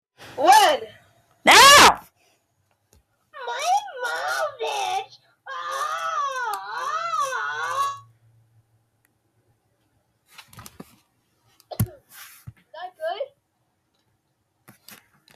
voice record soundboard # meme